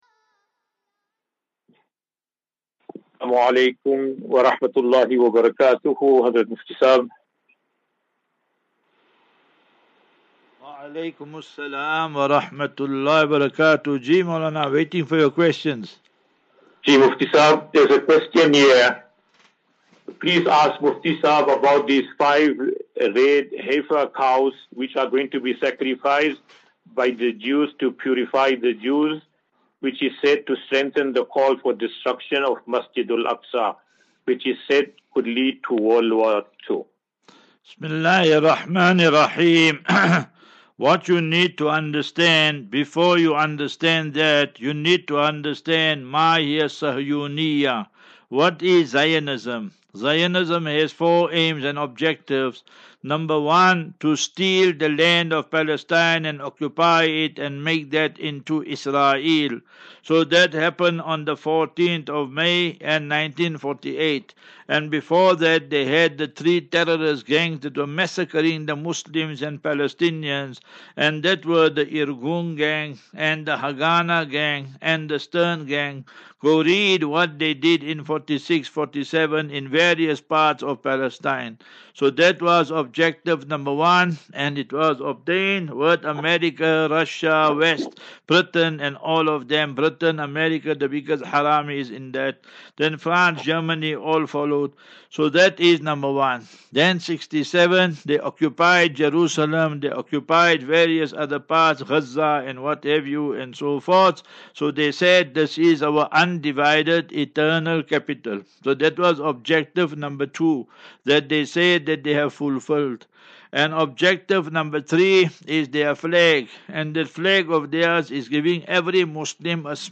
As Safinatu Ilal Jannah Naseeha and Q and A 21 Mar 21 March 2024.